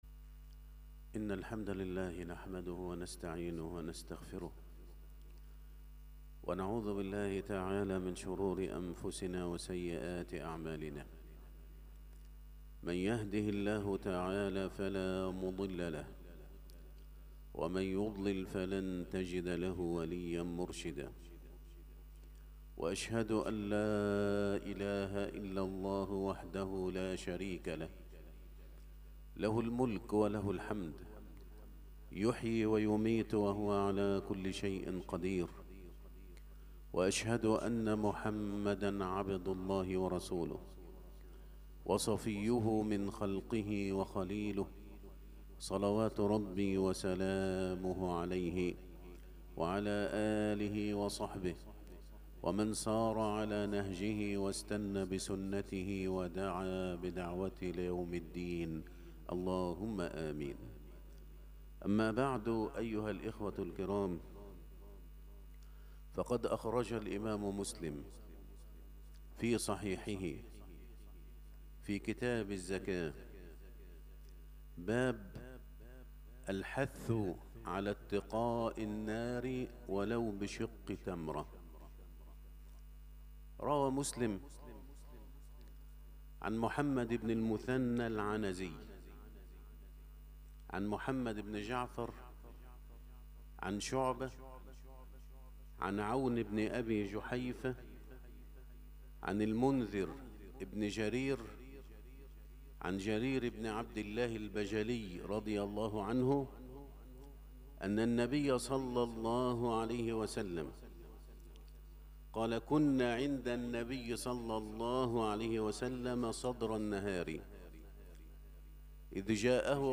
خطبه الجمعة
بمجمع التوحيد بالمنصورة